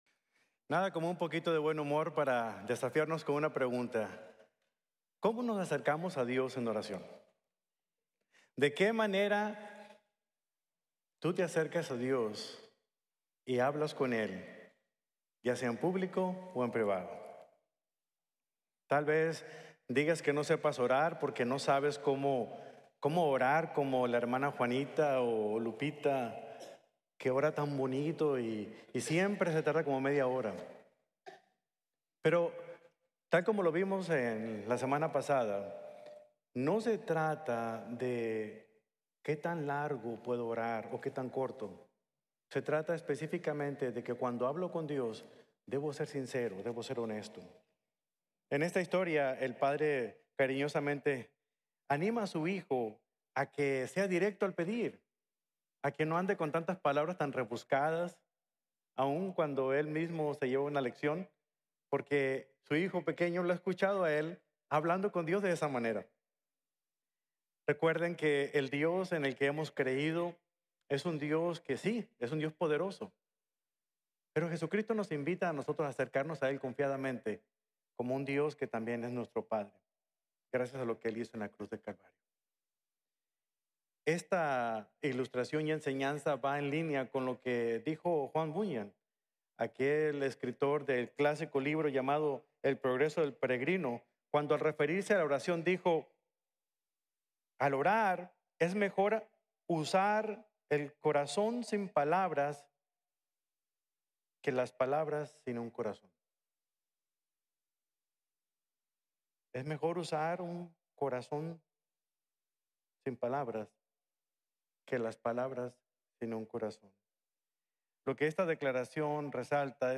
En Busca del Reino de Dios Mediante la Oración | Sermon | Grace Bible Church